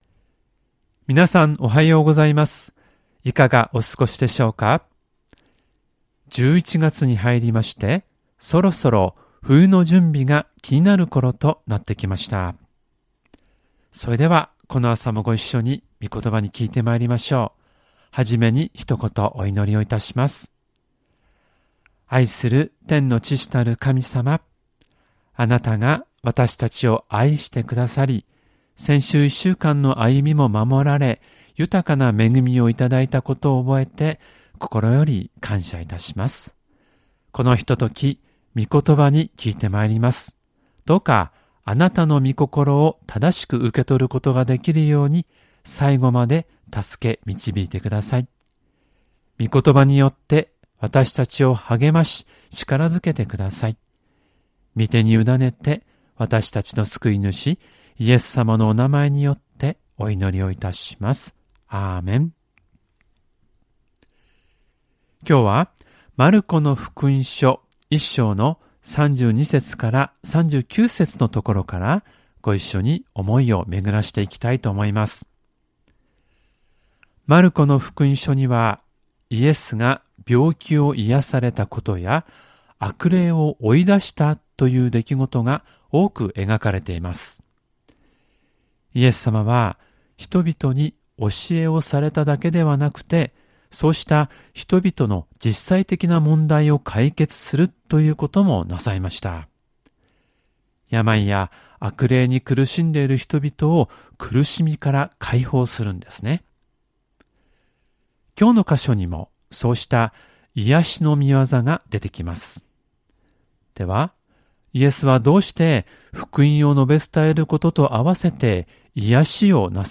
●主日礼拝メッセージ（赤文字をクリックするとメッセージが聴けます。MP3ファイル）